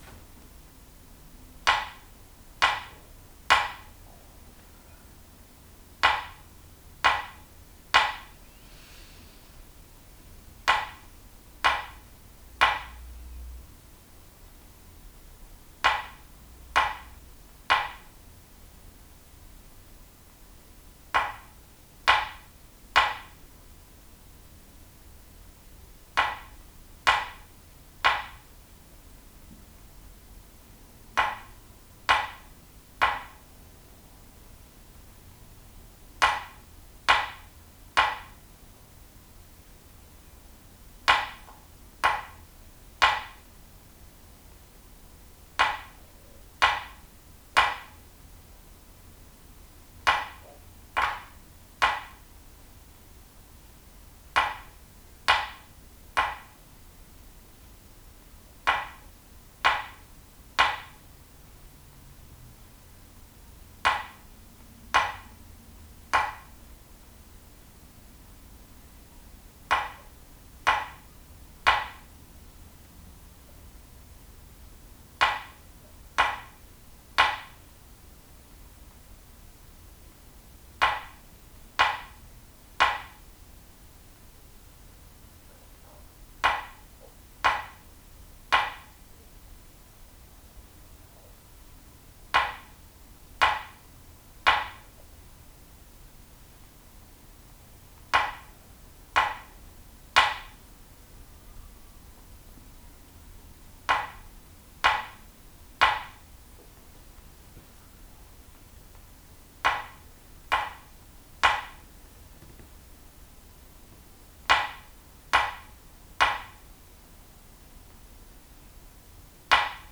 >>> Click Below <<< to hear 3 minutes of tap tone, using the solid spruce tapper.
solidsprucetappercooledit_3min.wav